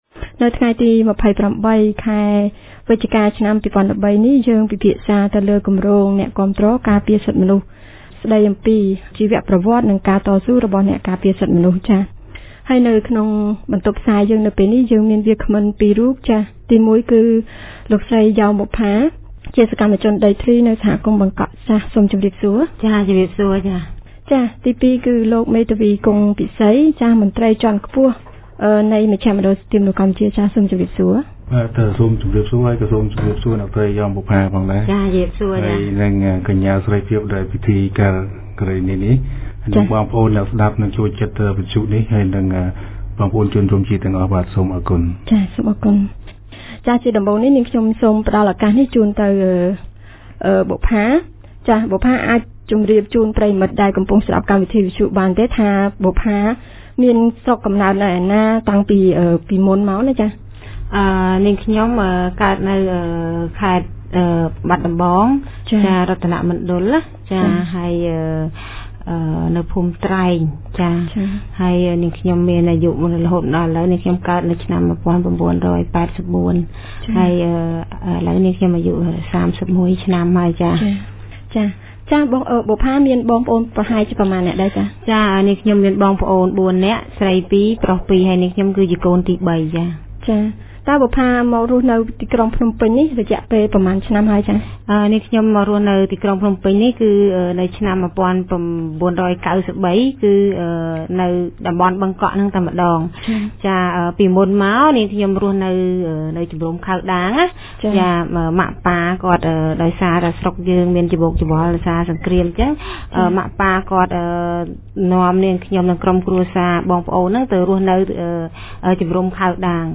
នៅថ្ងៃទី២៨ ខែវិចិ្ឆកា ឆ្នាំ២០១៣ គម្រោងអ្នកការពារសិទ្ធិមនុស្ស បានរៀបចំកម្មវិធីវិទ្យុពិភាក្សាស្តីពី "អ្នកការពារសិទ្ធិមនុស្ស"។